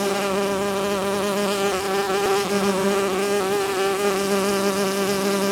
BeeBuzzing_Loop.wav